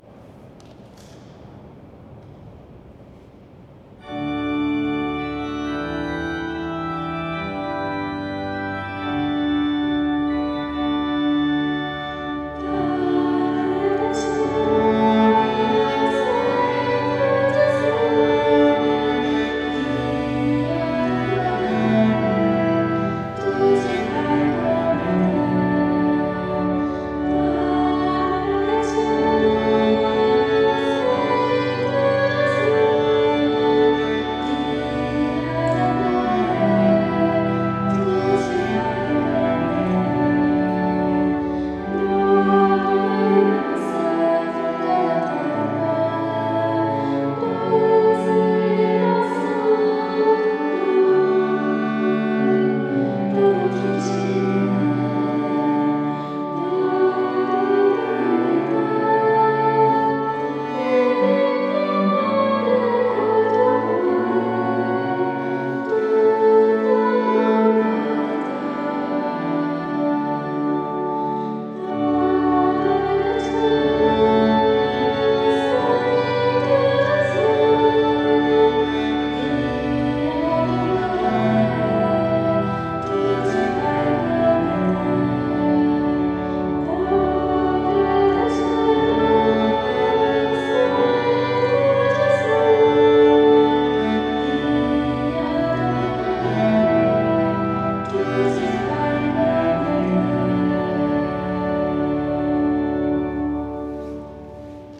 Particolarmente adatto come canto alla Comunione o allo Spezzare del Pane, ci conduce nell’esperienza di Cristo come nutrimento quotidiano, apertura alla vita eterna, legame fra Parola ed Eucaristia. Le terzine, in cui si articola il brano, danno slancio e vitalita’ al pezzo: se ne curi un’esecuzione viva e dinamica.